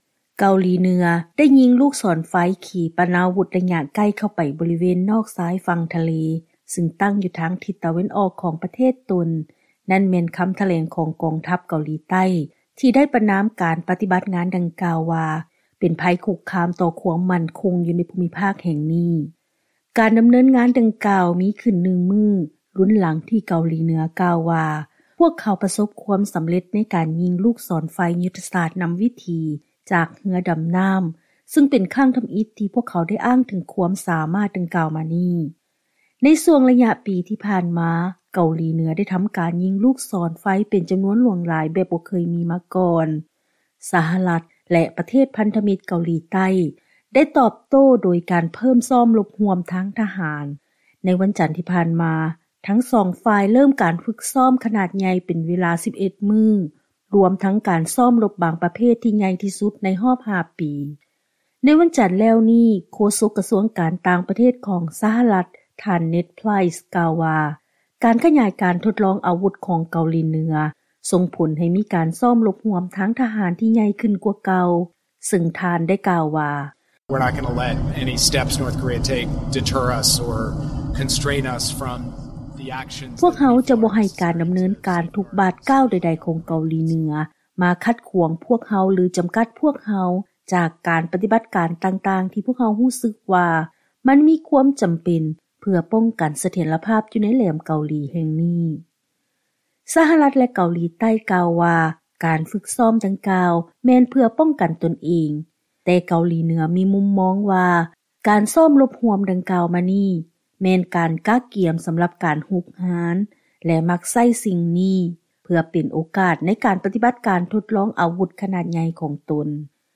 ເຊີນຟັງລາຍງານກ່ຽວກັບ ການທົດລອງຍິງລູກສອນໄຟ ຂອງເກົາຫຼີເໜືອ ທ່າມກາງການຊ້ອມລົບຮ່ວມຂອງ ສະຫະລັດ ແລະເກົາຫຼີໃຕ້